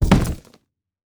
Foley Sports / Skateboard / Bail D.wav